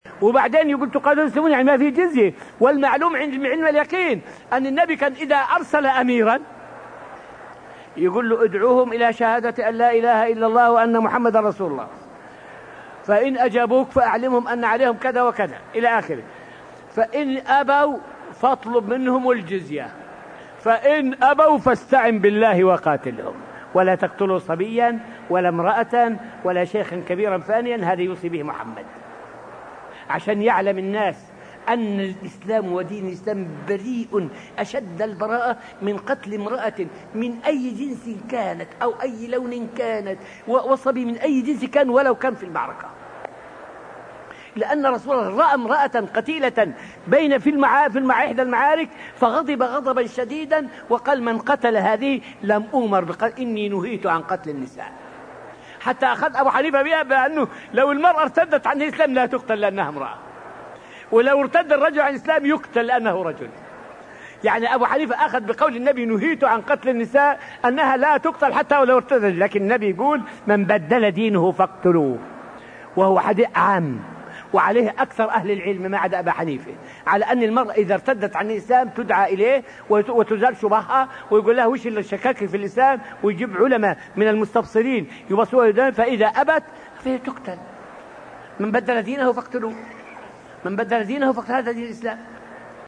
فائدة من الدرس الثاني والعشرون من دروس تفسير سورة البقرة والتي ألقيت في المسجد النبوي الشريف حول أن الإسلام بريء من قتل النساء والأطفال.